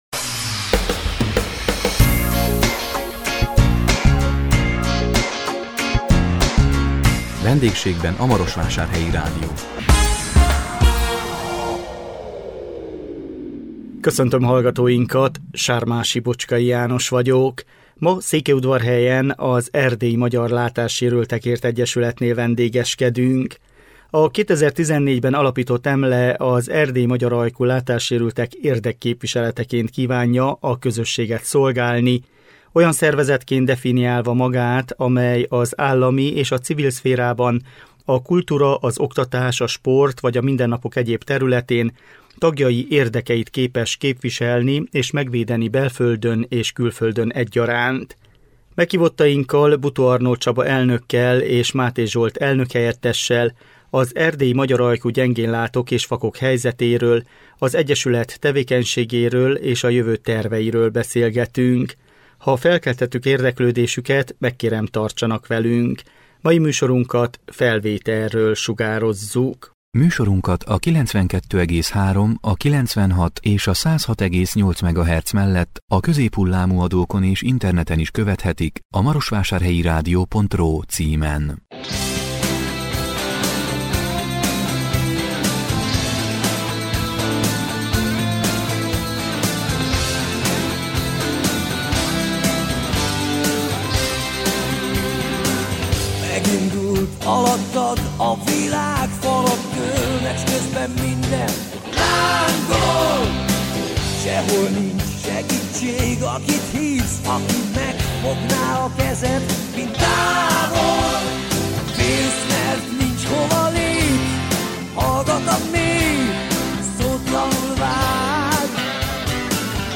A 2021 szeptember 16-án jelentkező VENDÉGSÉGBEN A MAROSVÁSÁRHELYI RÁDIÓ című műsorunkban Székelyudvarhelyen, az Erdélyi Magyar Látásérültekért Egyesületnél vendégeskedtünk. A 2014-ben alapított EMLE, az erdélyi magyar ajkú látássérültek érdekképviseleteként kívánja a közösséget szolgálni, olyan szervezetként definiálva magát, amely az állami és a civil szférában a kultúra-, az oktatás-, a sport vagy a mindennapok egyéb területén, tagjai érdekeit képes képviselni és megvédeni belföldön és külföldön egyaránt.